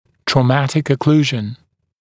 [trɔː’mætɪk ə’kluːʒn][тро:’мэтик э’клу:жн]травматическая окклюзия, травмирующий прикус